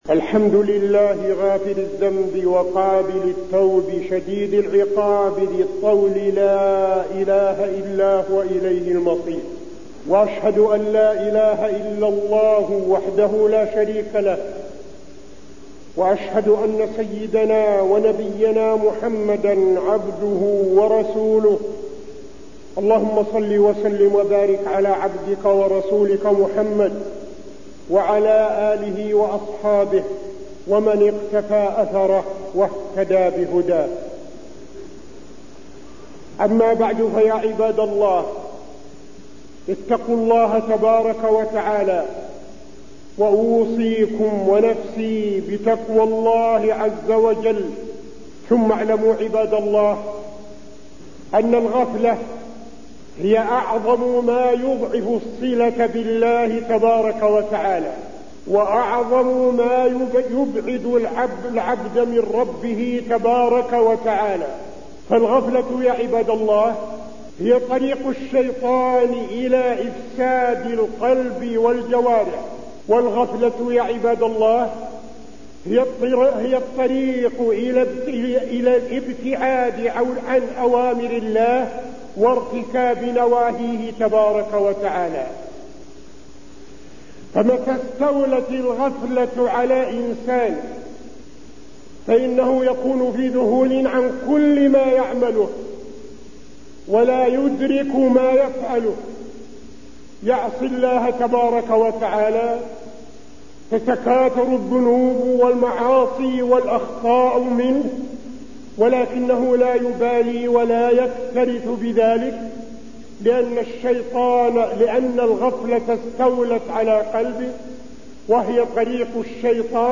تاريخ النشر ١٥ محرم ١٤٠٧ هـ المكان: المسجد النبوي الشيخ: فضيلة الشيخ عبدالعزيز بن صالح فضيلة الشيخ عبدالعزيز بن صالح التوبة